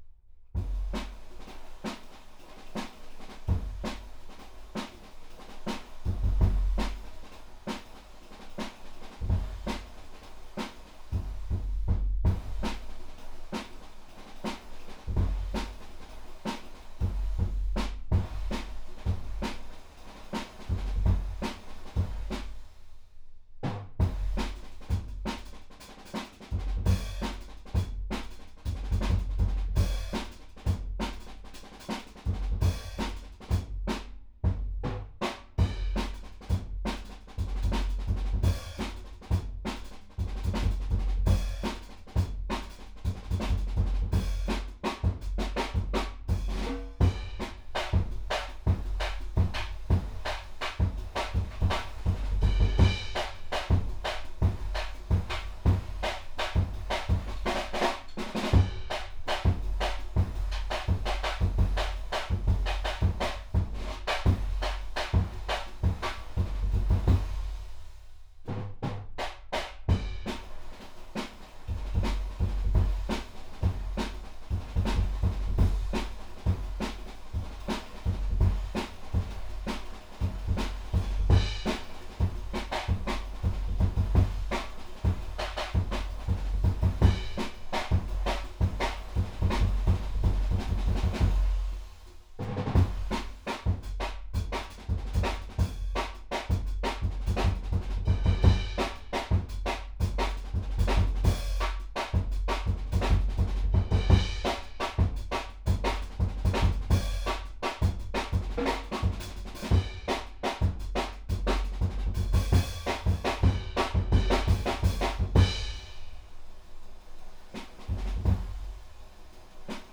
Index of /4 DRUM N BASS:JUNGLE BEATS/BEATS OF THE JUNGLE THAT ARE ANTIFUNGAL!!/RAW MULTITRACKS
ROOM_1.wav